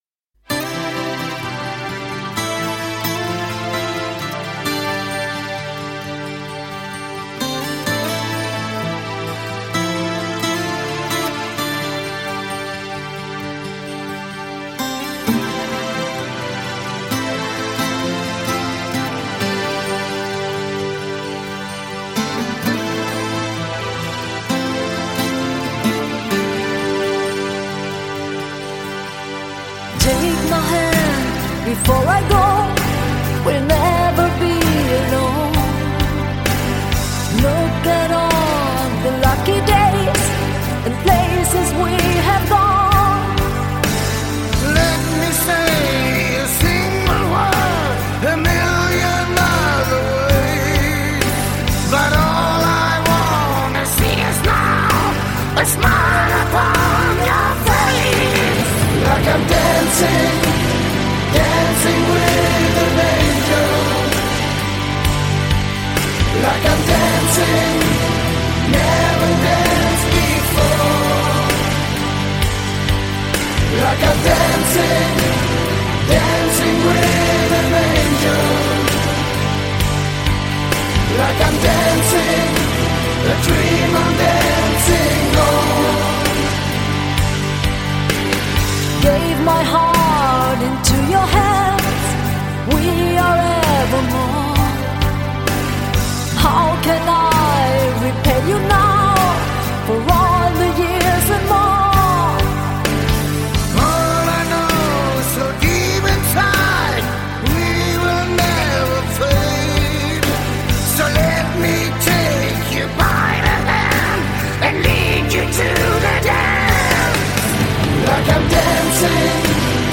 Жанр: Metal